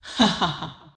Subject description: Perhaps an extremely rare basic main battle tank with a female voice   Reply with quote  Mark this post and the followings unread
I am not a woman, this is using AI technology to replace my vocals with AI tones.